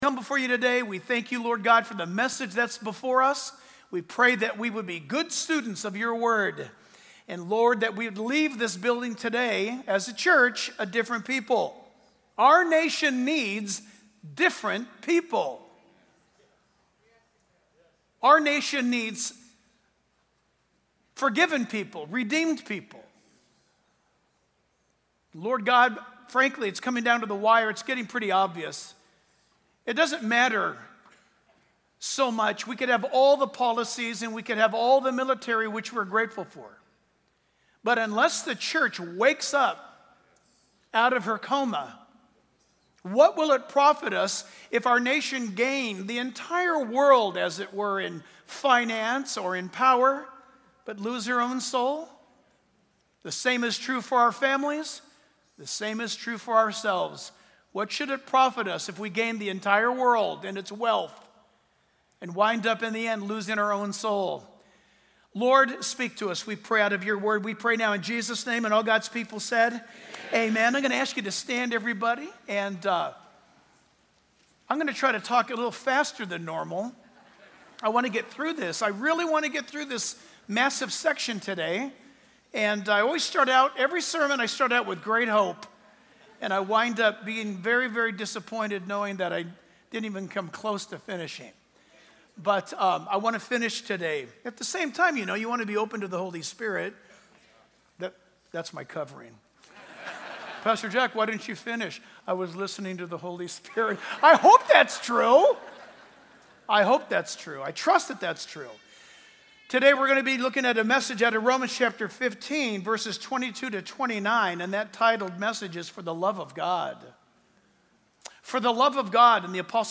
For The Love of God | Sermons